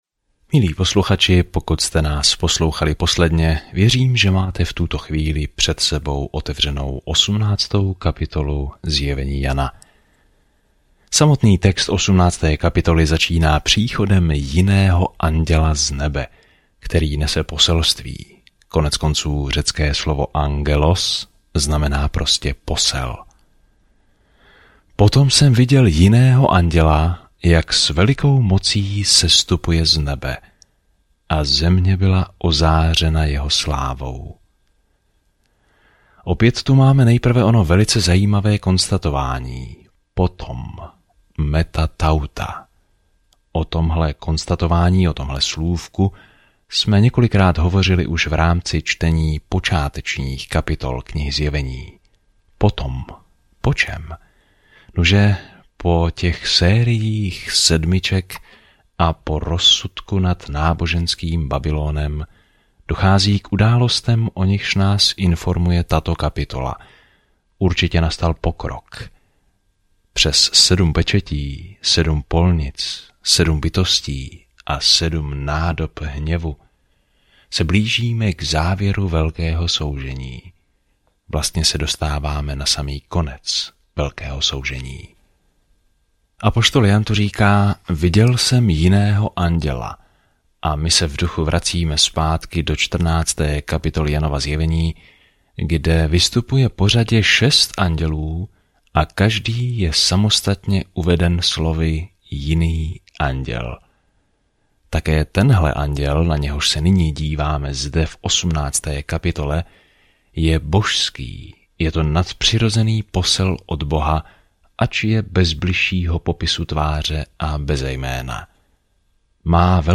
Písmo Zjevení 18:1-8 Den 52 Začít tento plán Den 54 O tomto plánu Zjevení zaznamenává konec rozsáhlé časové osy dějin s obrazem toho, jak bude se zlem konečně zacházeno a Pán Ježíš Kristus bude vládnout ve vší autoritě, moci, kráse a slávě. Denně procházejte Zjevení a poslouchejte audiostudii a čtěte vybrané verše z Božího slova.